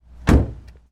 普锐斯 " 外观普锐斯在停车走人系列中，车门打开，头部发出提示音
描述：丰田普锐斯C驾驶员侧门打开和关闭，发出哔哔声。好混合动力发动机自动停止
标签： 关闭 普锐斯 丰田 打开
声道立体声